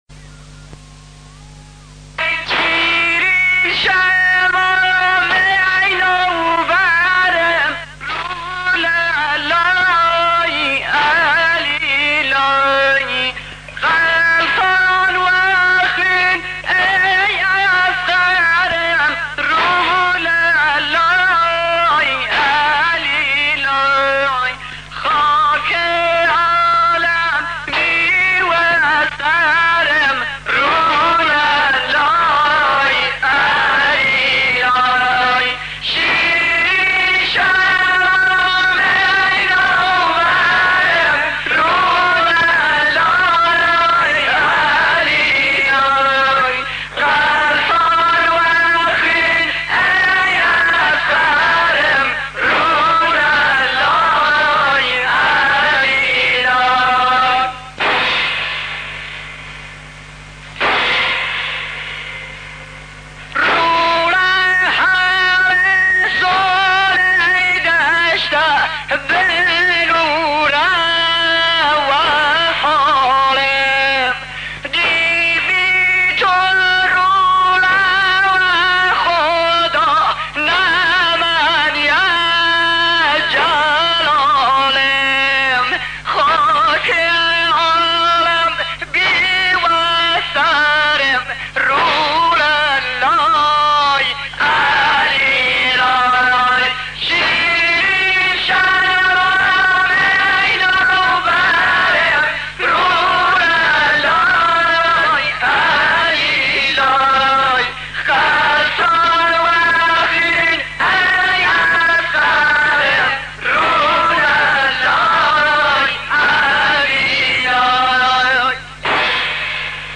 مداحی کردی